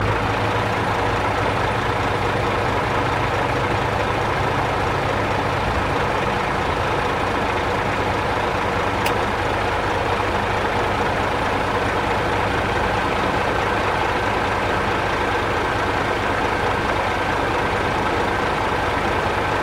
Качество записей проверено – никаких лишних шумов, только чистый звук техники.
Гул работающего Камаза на холостом ходу (грузовик-бетономешалка)